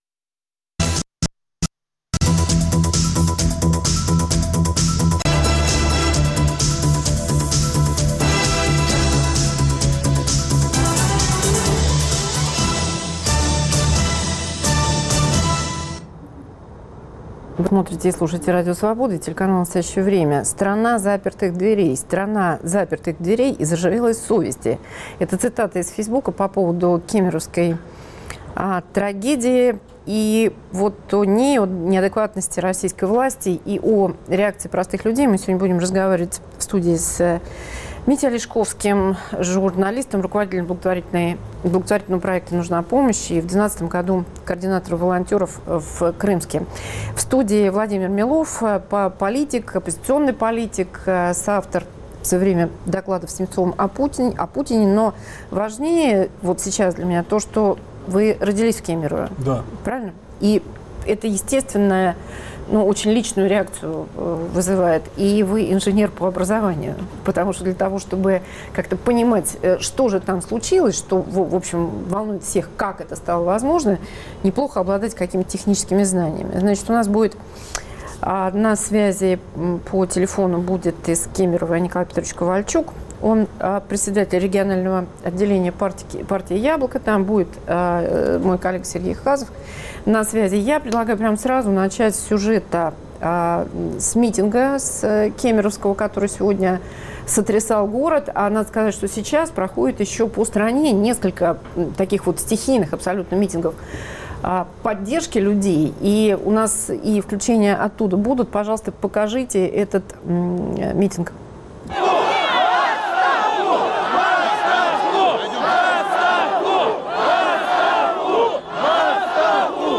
Репортажи с митингов в Кемерово, Москве и Петербурге